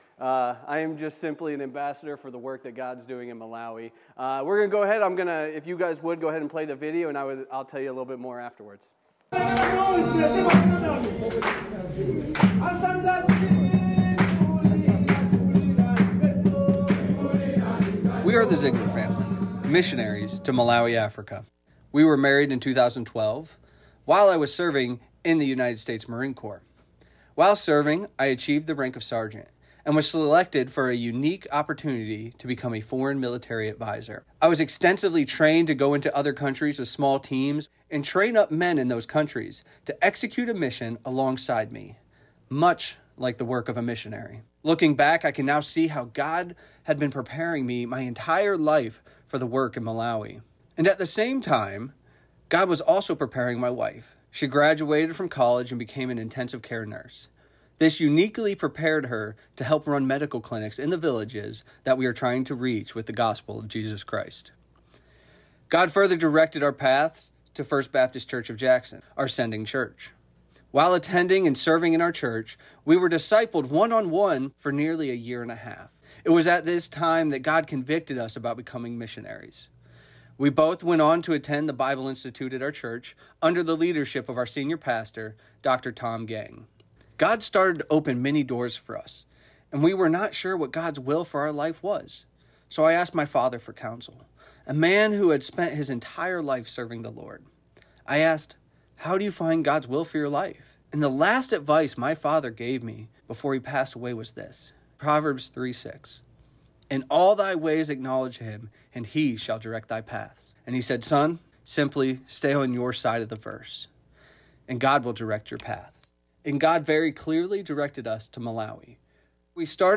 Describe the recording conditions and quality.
2025 Vision Conference